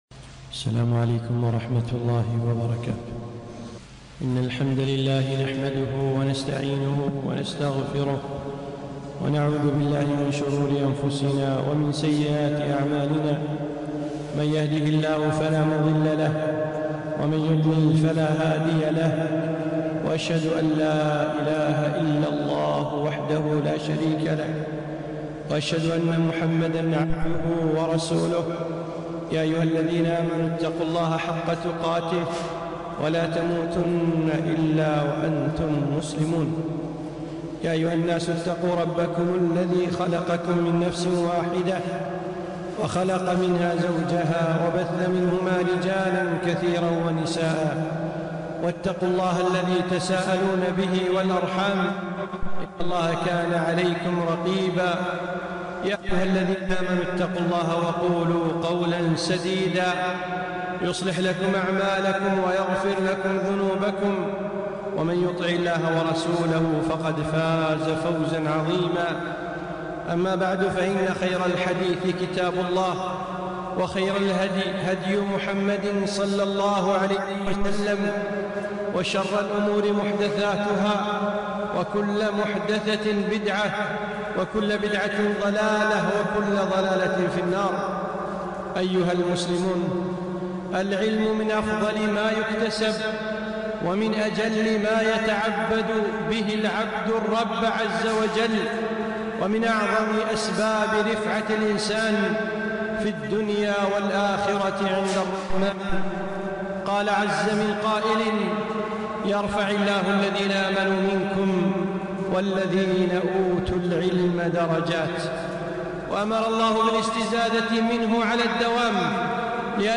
خطبة - العلمَ العلمَ